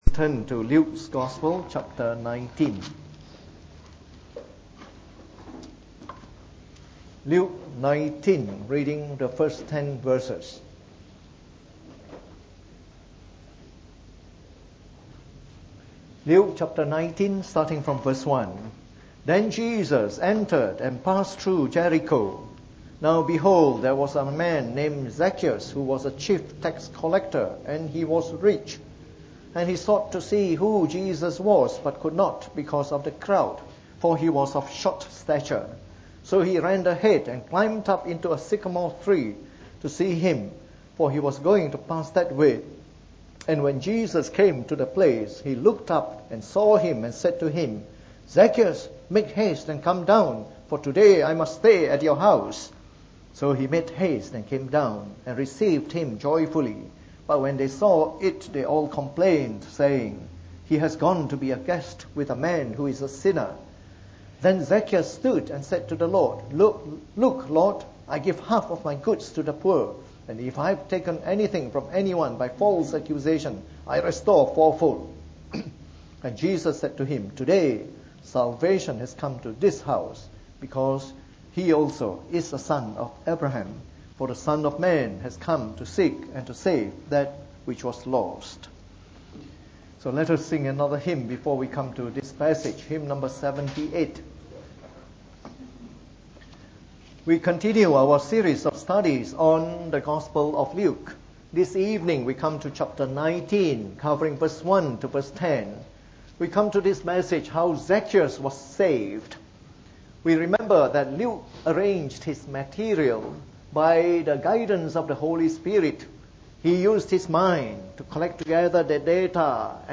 From our series on the “Gospel According to Luke” delivered in the Evening Service.